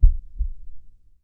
ACE3 / addons / medical / sounds / heart_beats / slow_2.wav